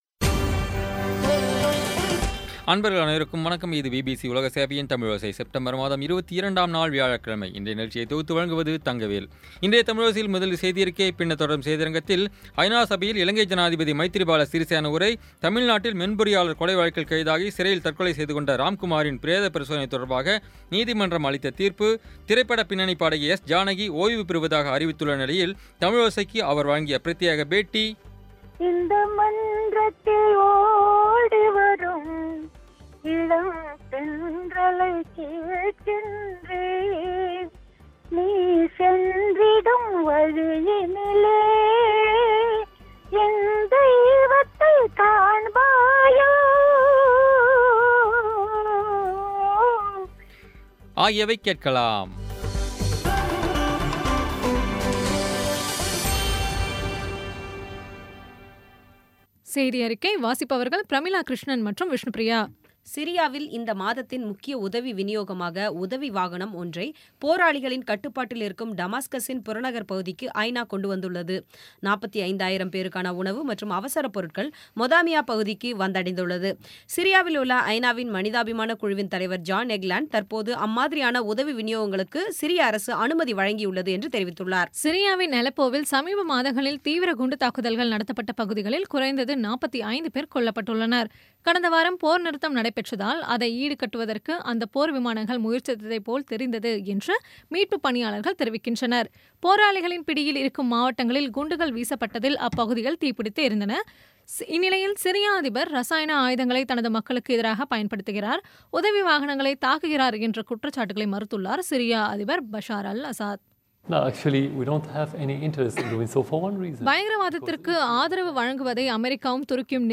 முதலில் செய்தியறிக்கை
ஜானகி, ஓய்வு பெறுவதாக அறிவித்துள்ள நிலையில், தமிழோசைக்கு அவர் வழங்கிய பிரத்யேகப் பேட்டி ஆகியவை கேட்கலாம்.